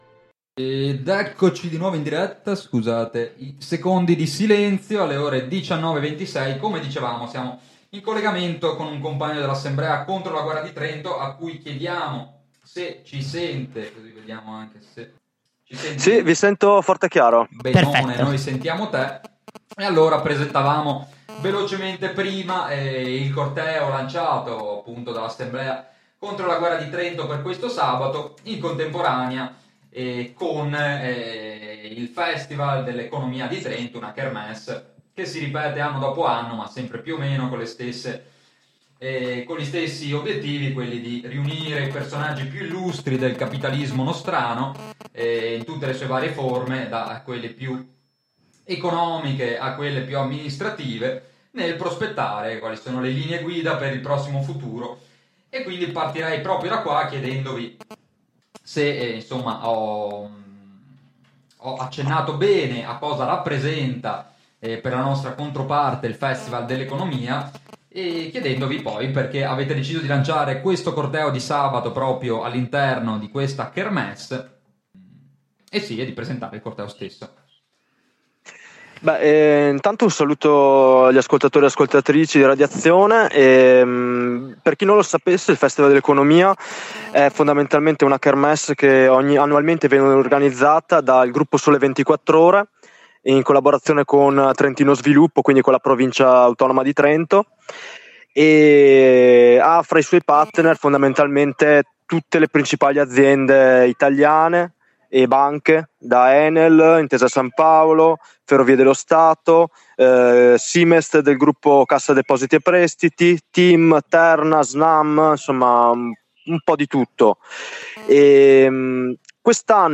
Collegamento con un compagno dell’Assemblea contro la guerra di Trento, ambito di discussione e lotta nato un paio di mesi fa per contrastare la propaganda di guerra a cui ci stanno abituando, che ha promosso per sabato questo 27 maggio un corteo contro la guerra durante il ‘rinomato’ Festival dell’Economia di Trento a cui parteciperanno diversi infausti personaggi tra politica, economia, tecnocrazia, generali, ex capi dei servizi segreti, presidenti di cofindustria e altri guerrafondai del genere.